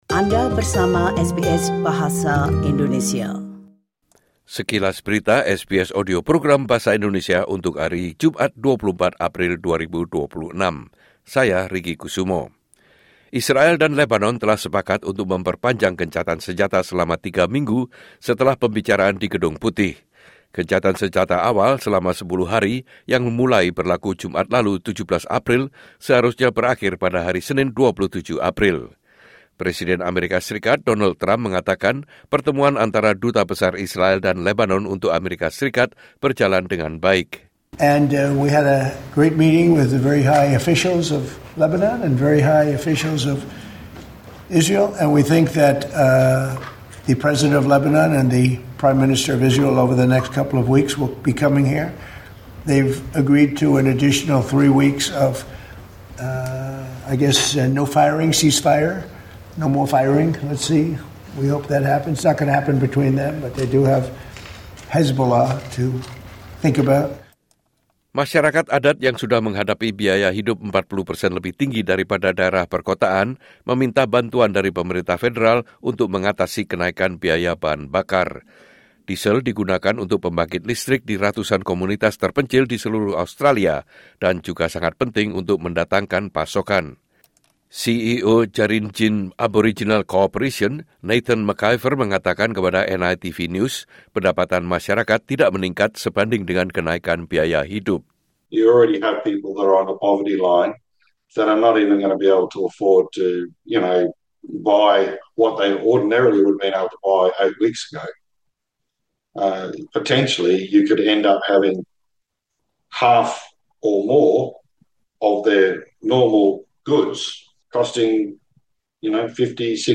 Latest News SBS Audio Program Bahasa Indonesia — Friday 24 April 2026